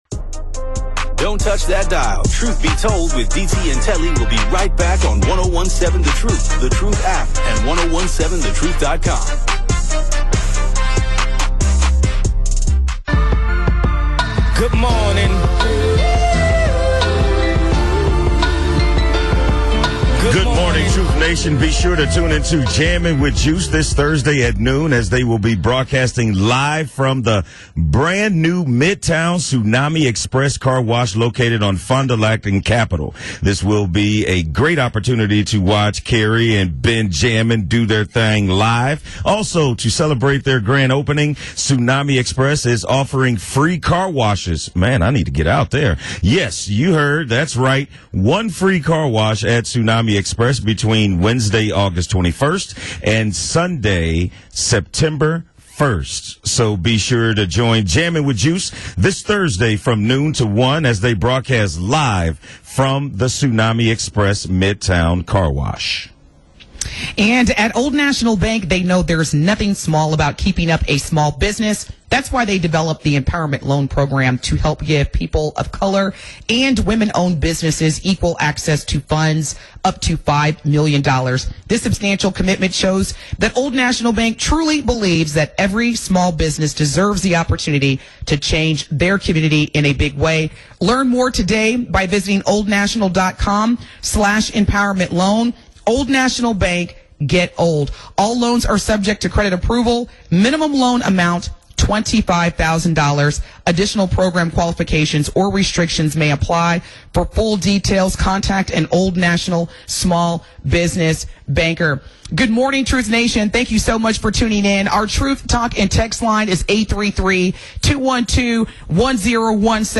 Obama speech at DNC 2024